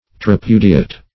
Meaning of tripudiate. tripudiate synonyms, pronunciation, spelling and more from Free Dictionary.
Search Result for " tripudiate" : The Collaborative International Dictionary of English v.0.48: Tripudiate \Tri*pu"di*ate\, v. i. [L. tripudiare, tripudiatum.]